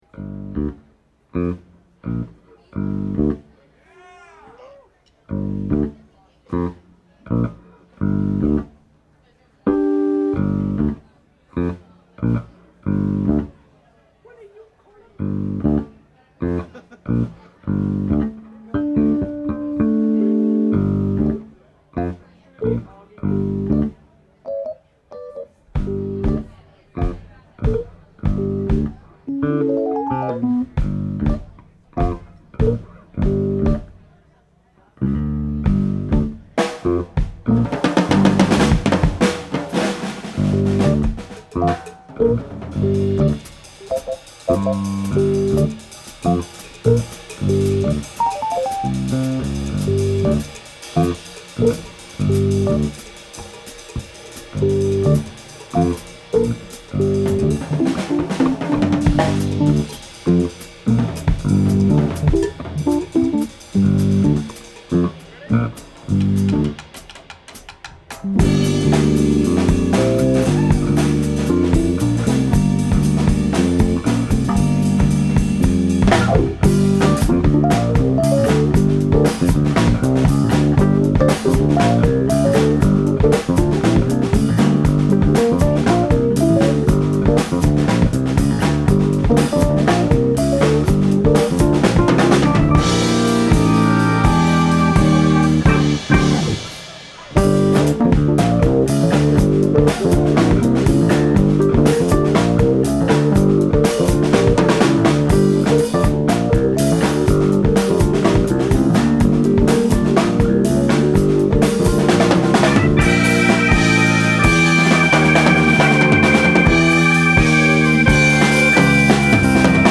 Last Concert Café, 10/23/04
keys
drums
Bass. CD Release concert